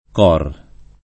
cor [ k 0 r ] tronc. di core